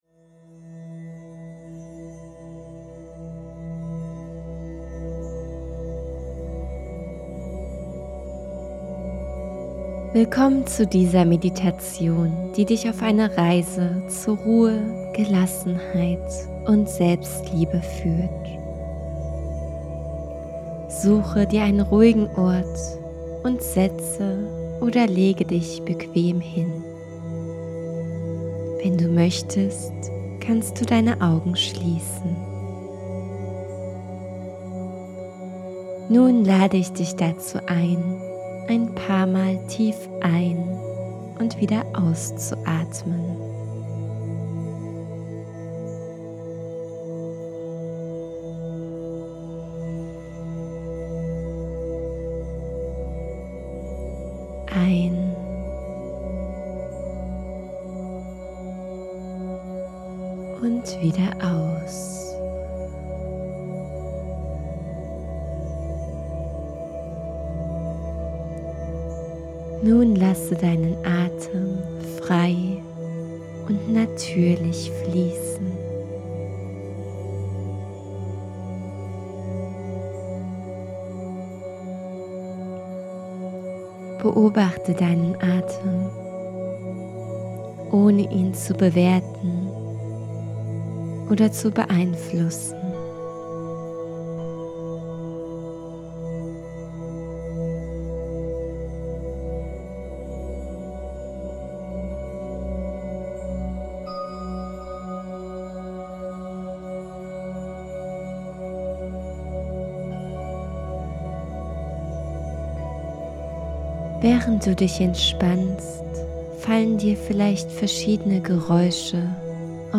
Glaubenssätze-Meditation